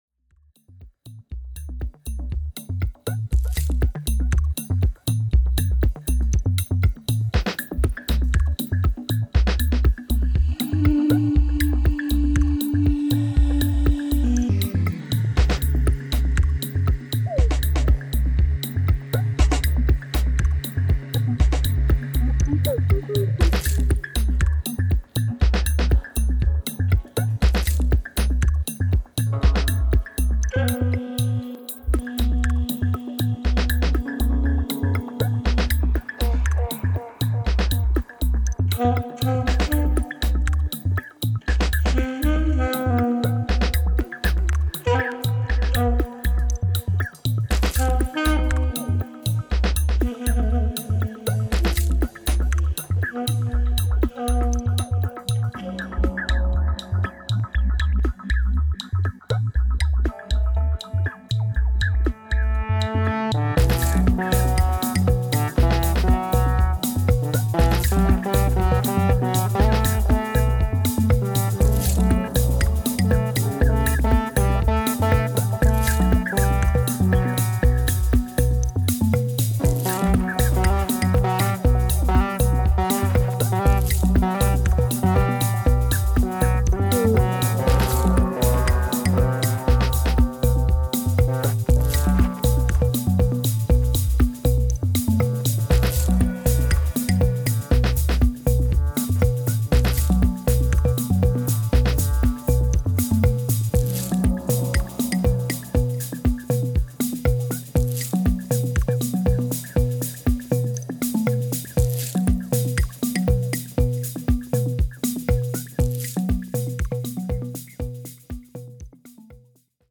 House
4 freshly baked tracks straight outta the MPC